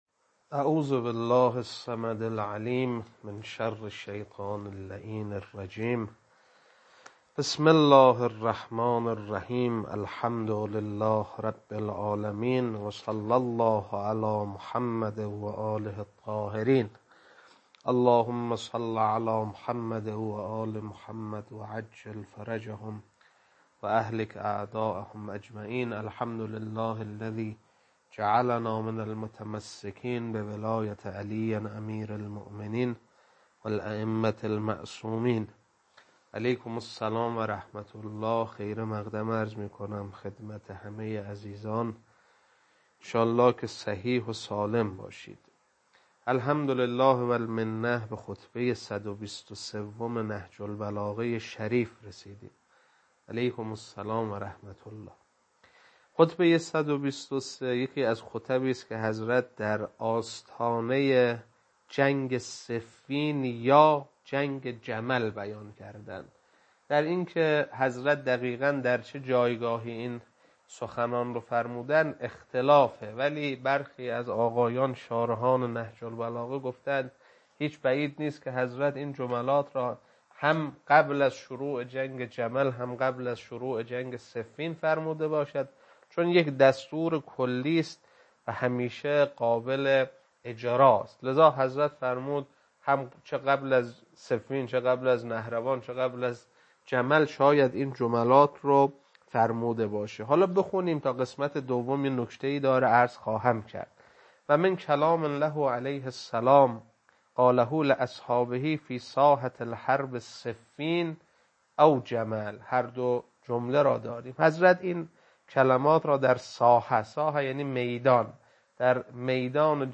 خطبه 123.mp3